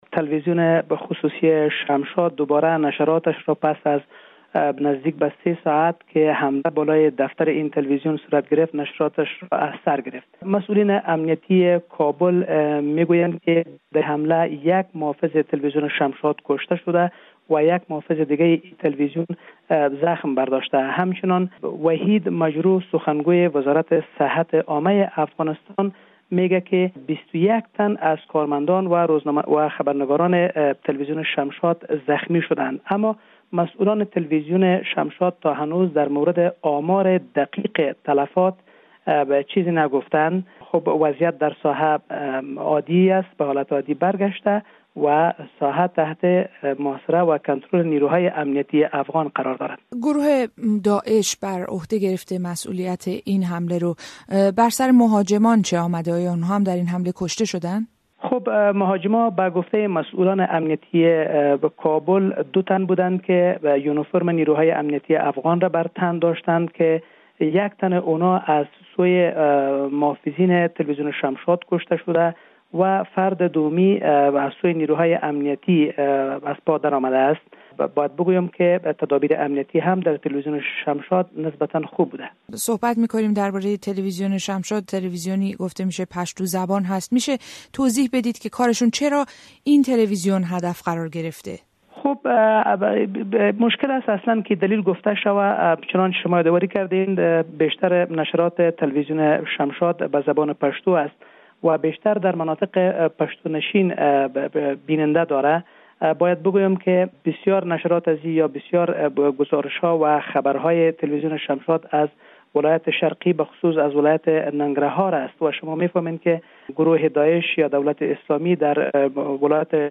حمله به یک ایستگاه تلویزیونی در کابل؛ گفتگویی